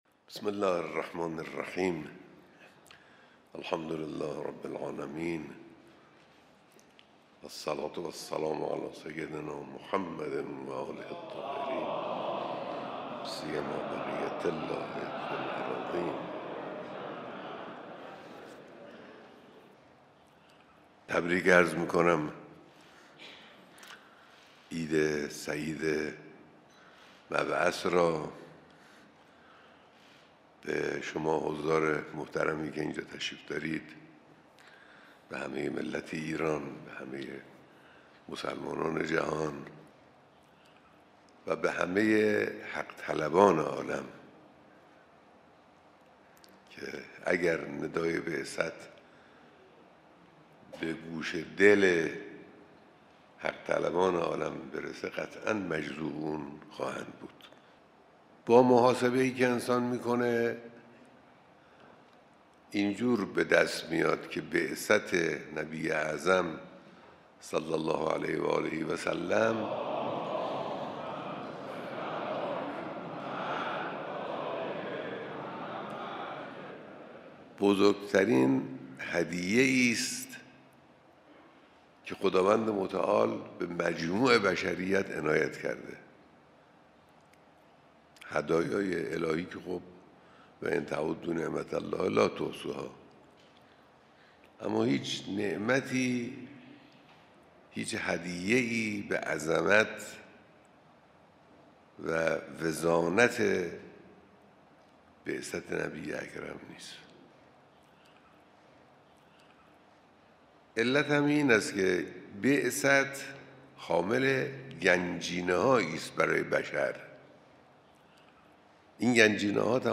صوت | بیانات رهبر انقلاب در دیدار مسئولان نظام و سفرای کشورهای اسلامی